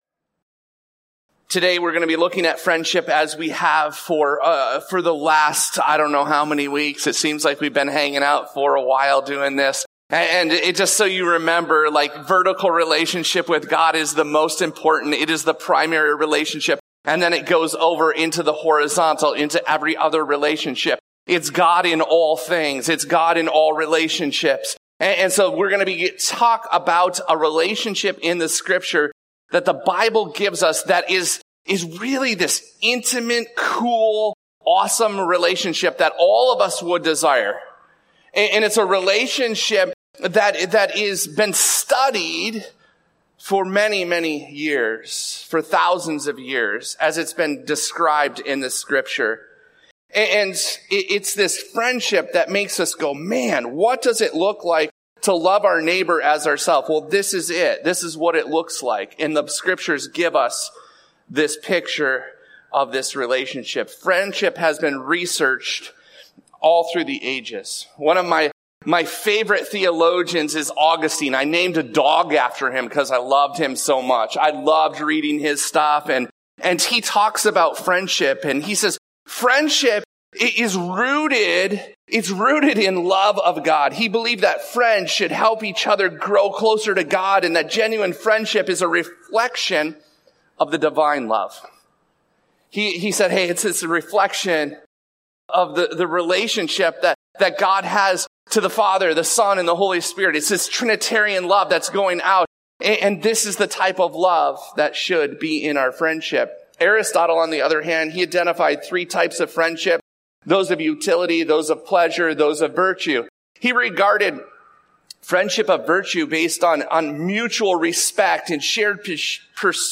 This podcast episode is a Sunday message from Evangel Community Church, Houghton, Michigan, August 4, 2024.